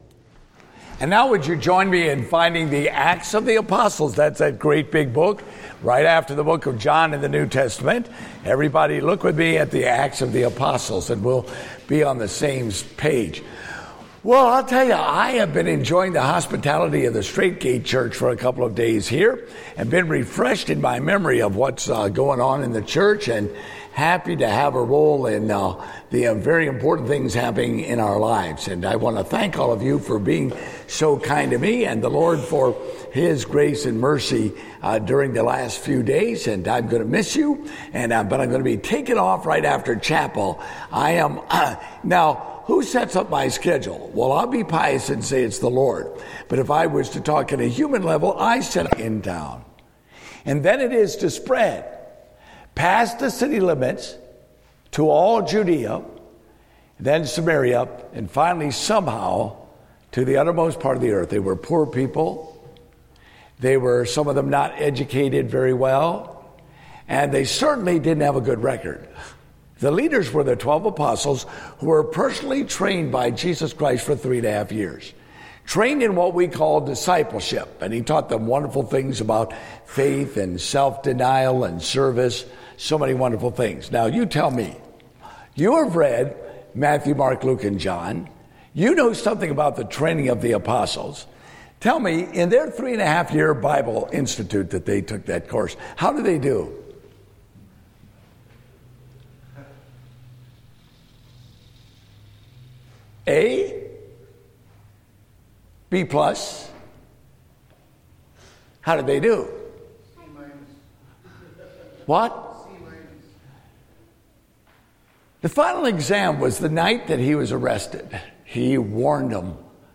Date: August 22, 2015 (Family Camp)